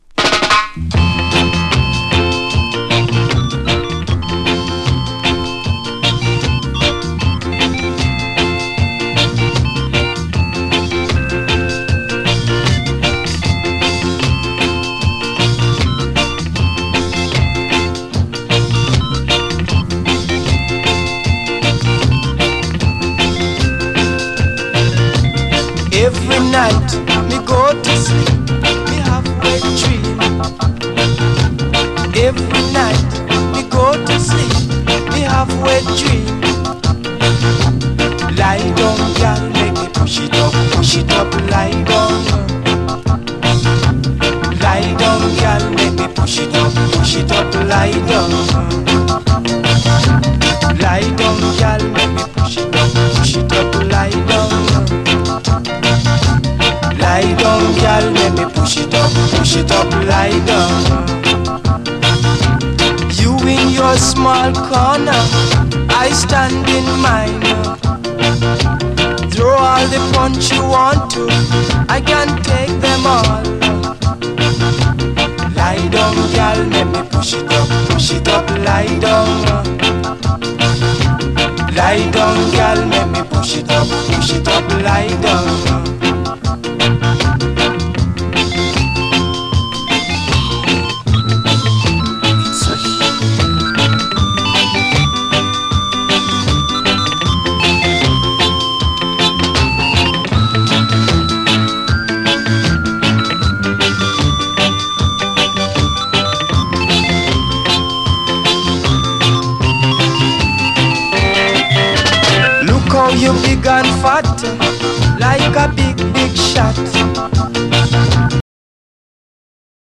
REGGAE, 7INCH
乾いたオルガンのファンキー・レゲエ！